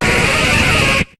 Cri de Crustabri dans Pokémon HOME.